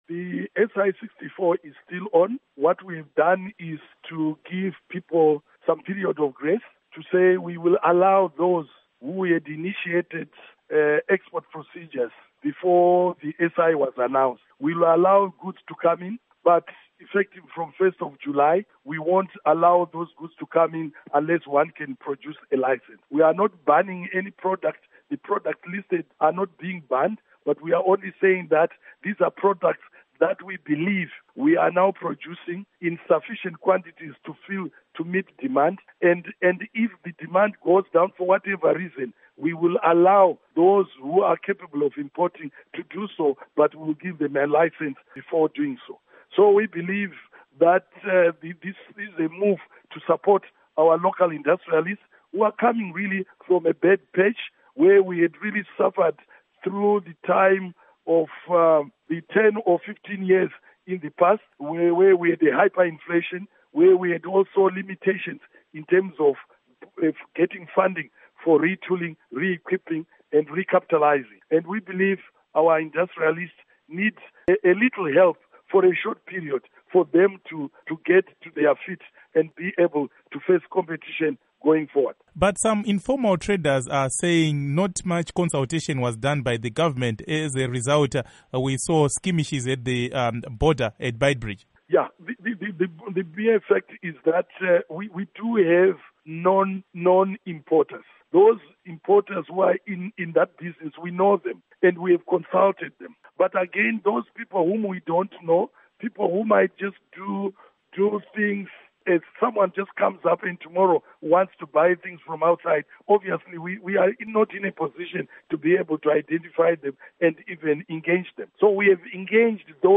Interview With Mike Bimha on Import Ban Law